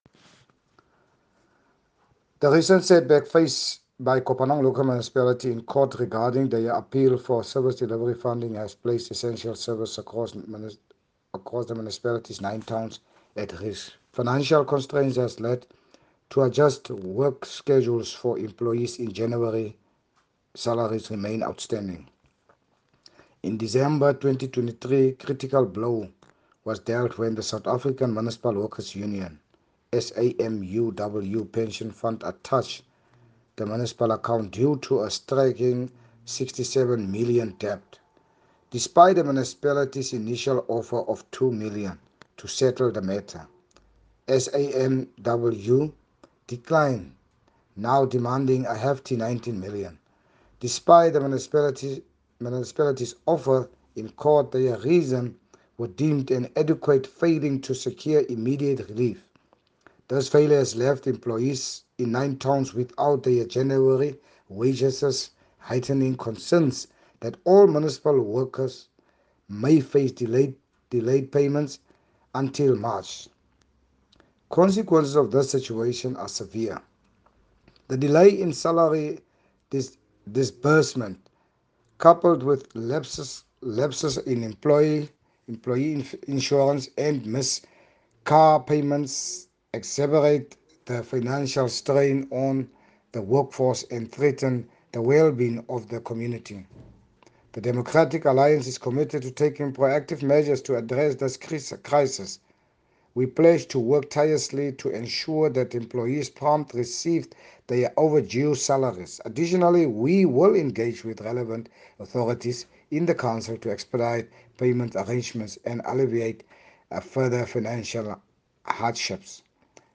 English and Afrikaans soundbites by Cllr Richard van Wyk and